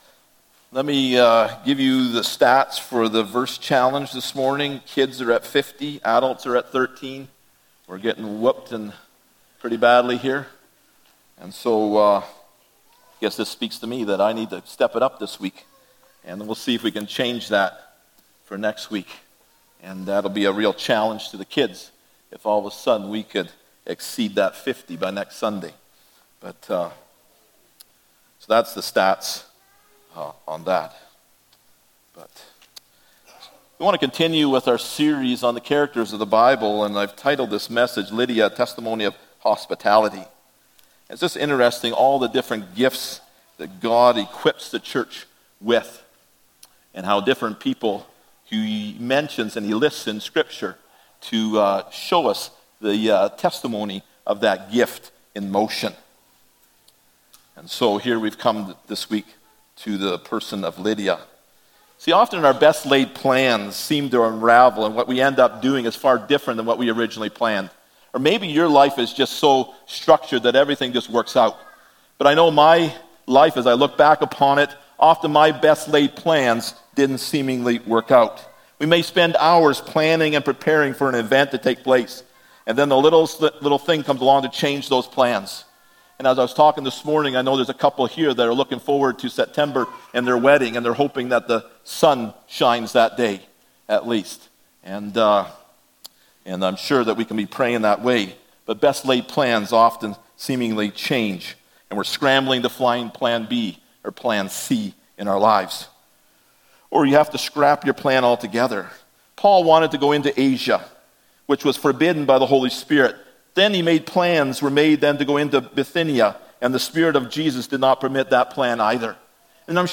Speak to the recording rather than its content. Characters of the Bible Passage: Acts 16: 13-15, 40 Service Type: Sunday Morning « Hannah